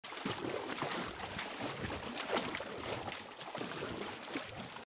action_swim.mp3